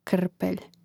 kŕpelj krpelj